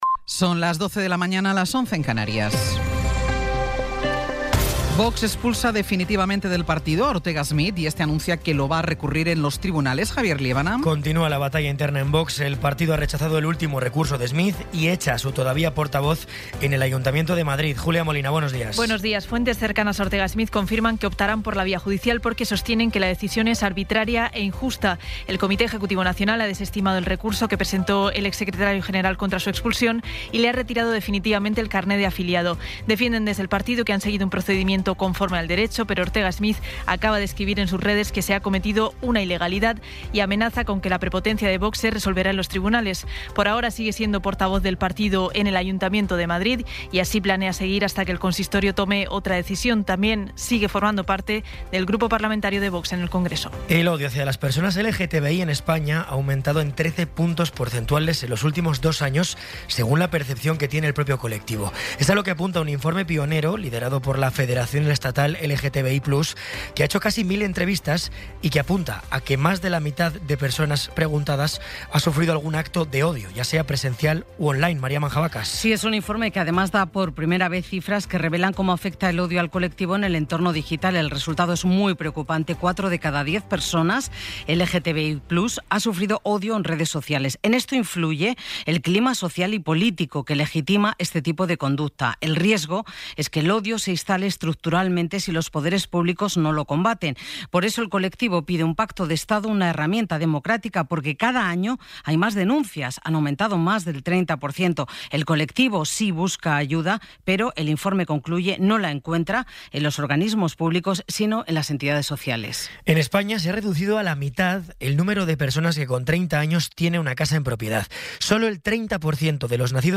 Resumen informativo con las noticias más destacadas del 16 de abril de 2026 a las doce.